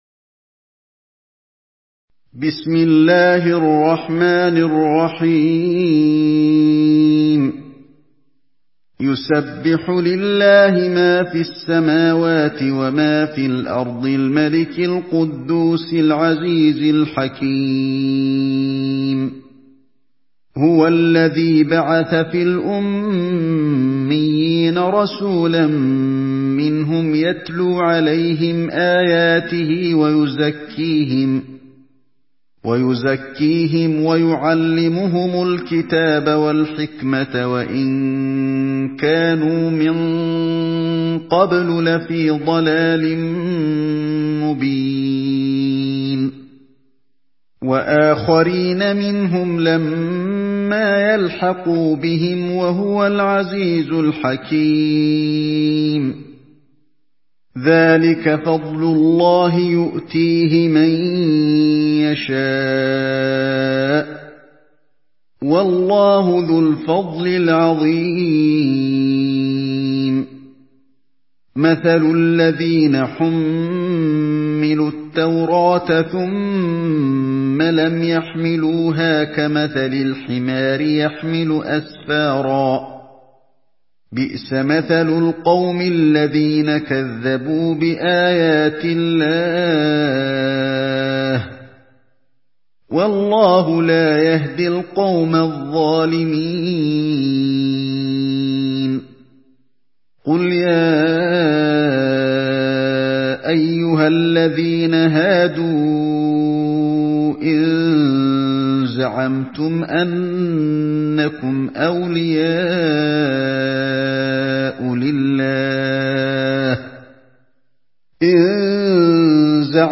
Surah الجمعه MP3 by علي الحذيفي in حفص عن عاصم narration. Listen and download the full recitation in MP3 format via direct and fast links in multiple qualities to your mobile phone.